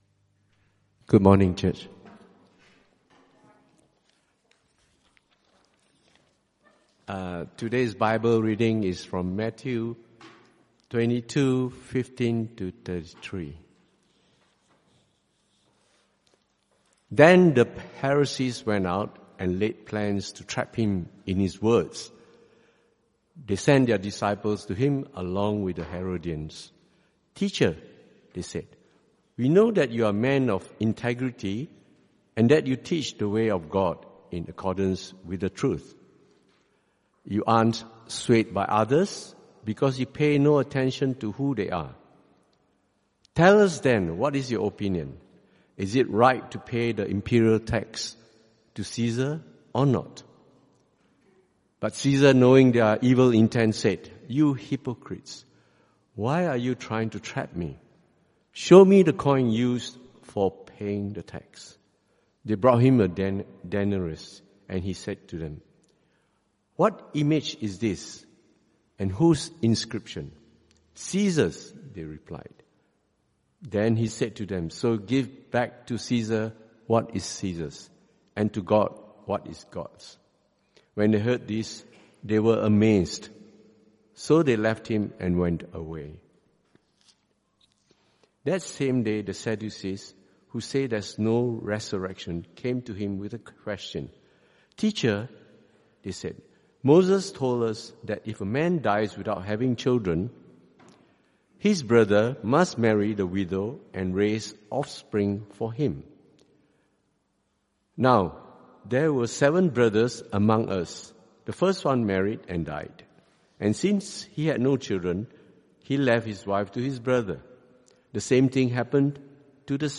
CBC Service: 30 Nov 2025 Series
Type: Sermons